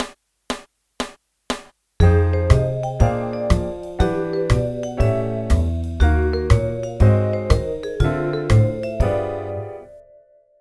practicing jazz piano
4 major key centres, new
Here’s a string of four major II – V’s, in which I improvise through their key centres.
4-key-centres-mix.mp3